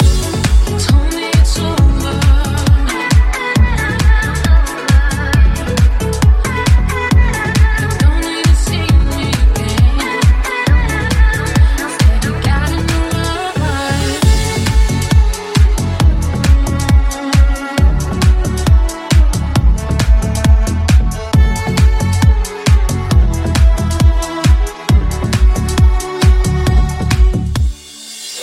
light deep house releases
Genere: deep, slap, tropical, ethno, remix